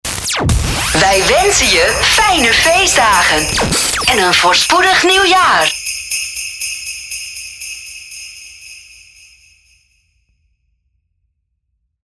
• Sound Effects (SFX), zoals hoorbaar in voorbeeld.
• Geluid geoptimaliseerd